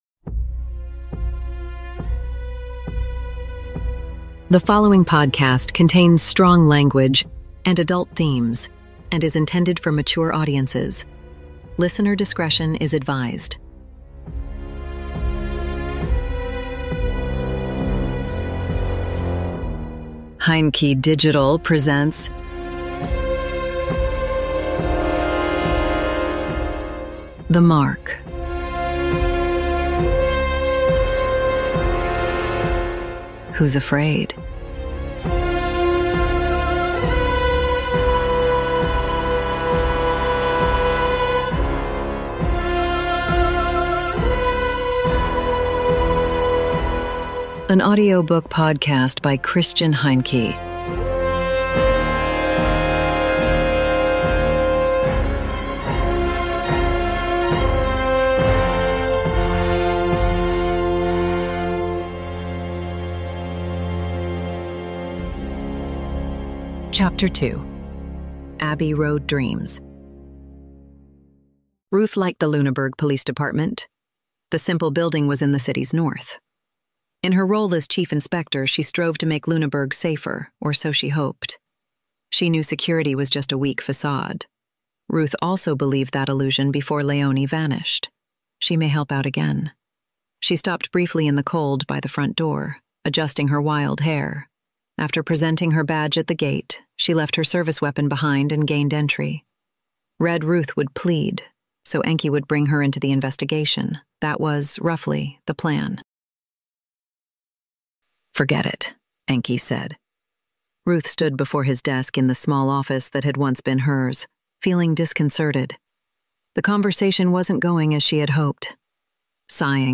The Mark - Audiobook Podcast